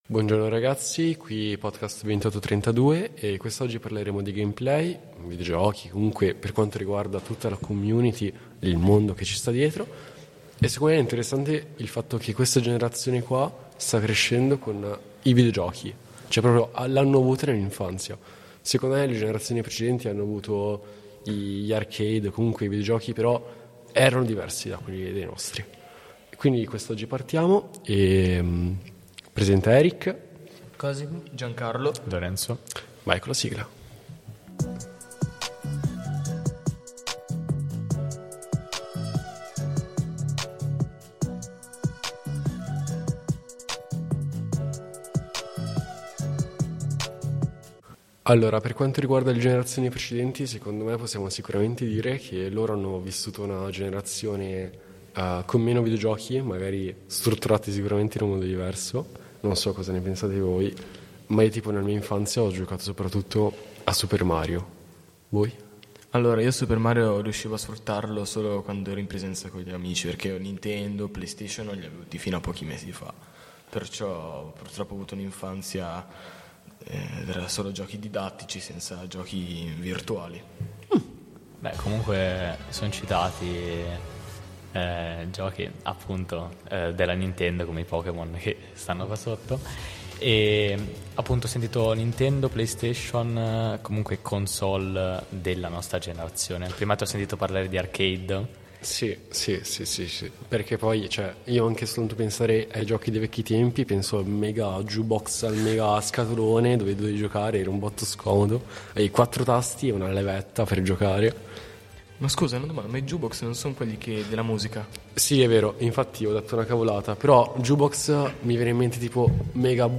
Siamo un gruppo di ragazzi del territorio desiano, dai 14 ai 23 anni: universitari, commessi, appassionati di cinema, trap…